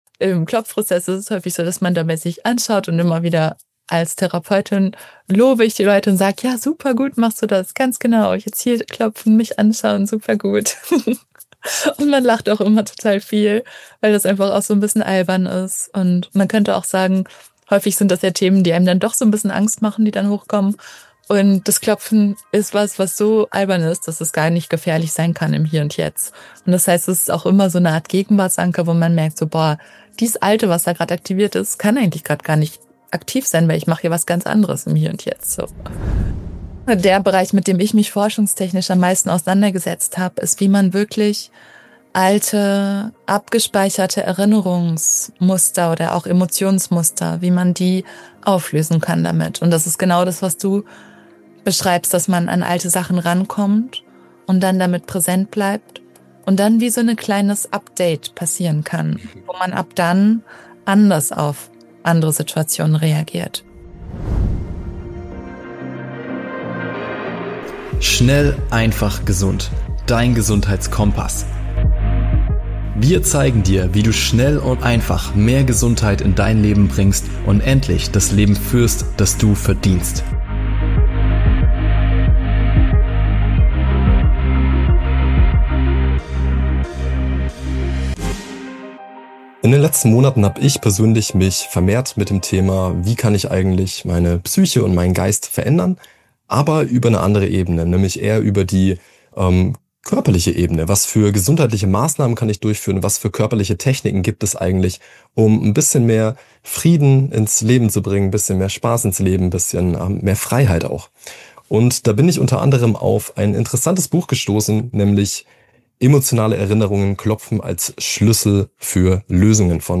Du erfährst, wie emotionale Blockaden, Stress und unverarbeitete Traumata durch gezielte Klopftechniken gelöst werden können – und warum dabei der Vagusnerv und die Polyvagal-Theorie eine zentrale Rolle spielen. Themen im Interview: ✅ Was ist Tapping – und wie funktioniert es?